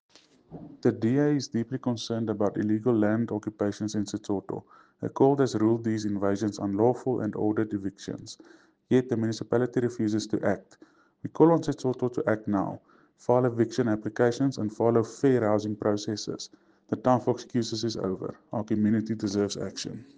English and Afrikaans soundbites by Cllr Jose Coetzee and Sesotho soundbite by Cllr Kabelo Moreeng.